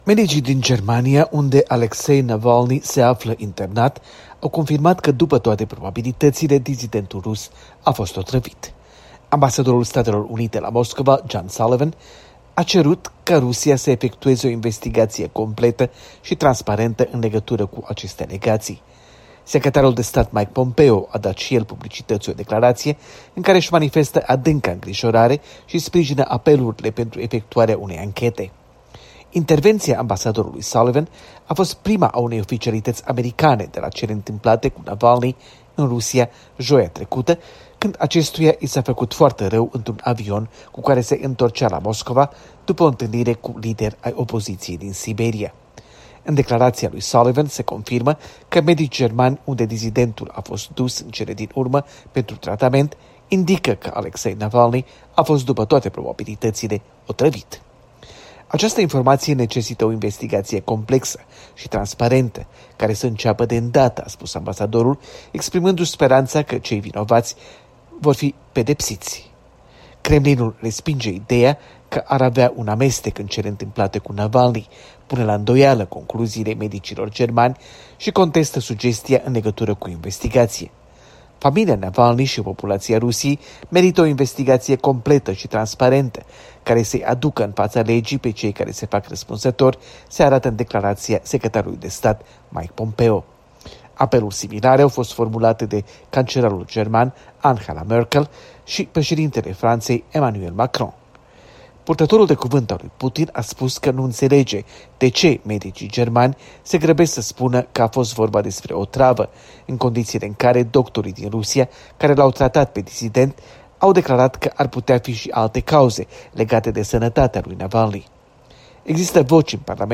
Corespondență de la Washington: cazul Alexei Navalnîi